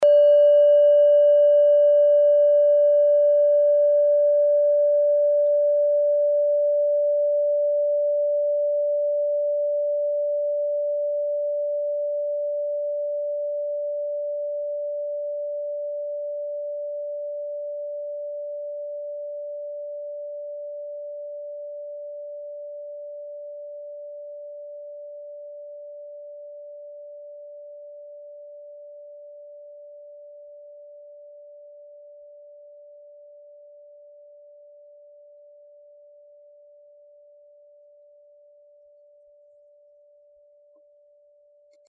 Kleine Klangschale Nr.1
Sie ist neu und ist gezielt nach altem 7-Metalle-Rezept in Handarbeit gezogen und gehämmert worden.
(Ermittelt mit dem Minifilzklöppel)
Der Marston liegt bei 144,72 Hz, das ist nahe beim "D".
kleine-klangschale-1.mp3